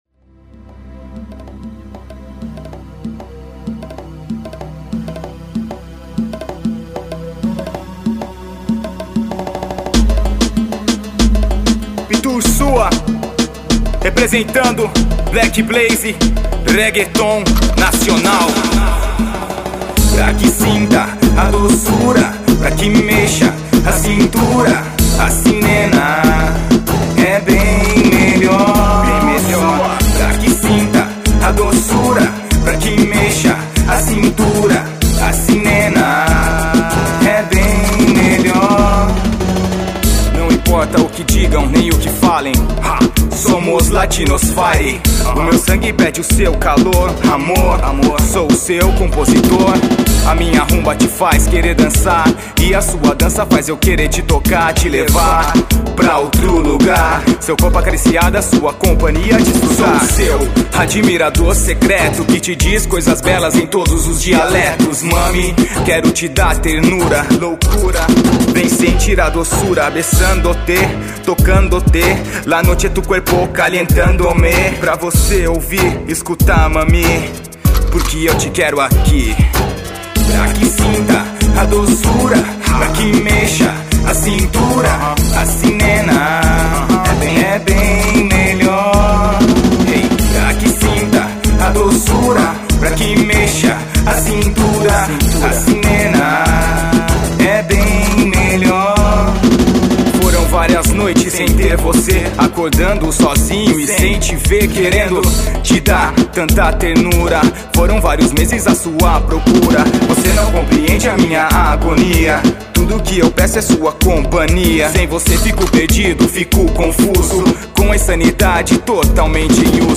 Reggaeton